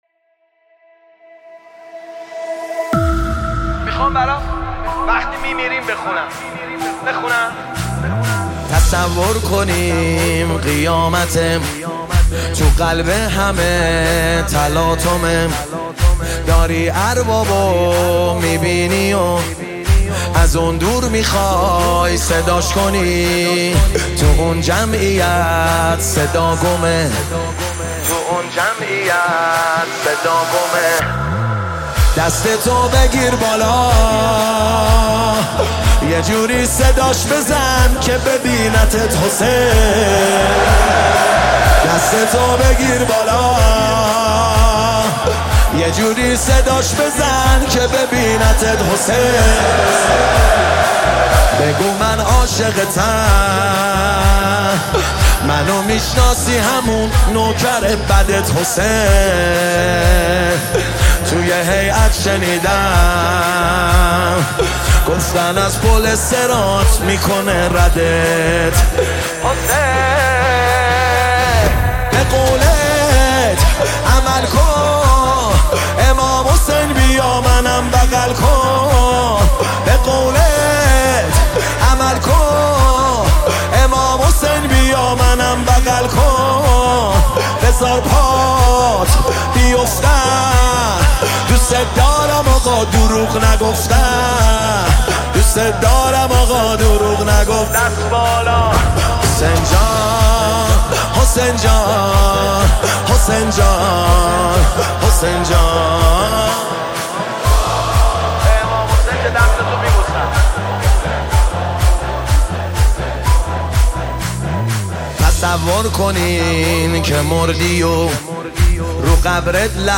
نماهنگ دلنشین